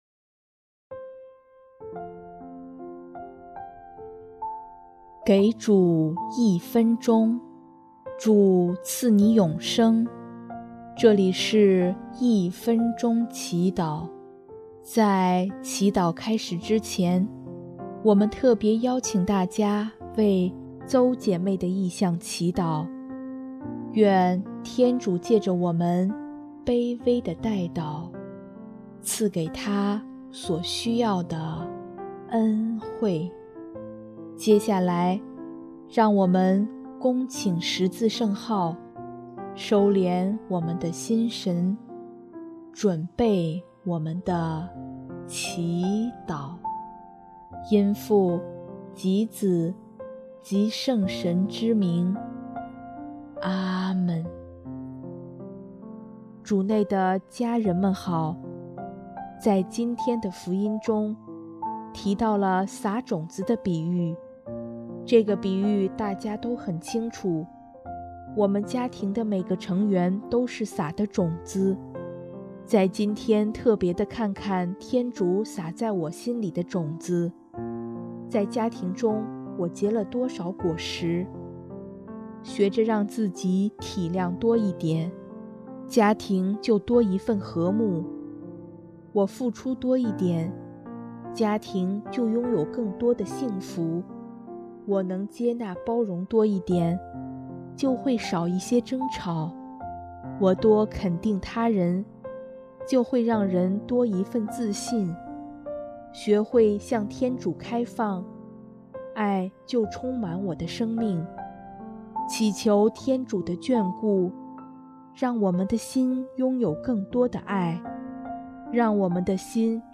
音乐：主日赞歌《芥子般的信德》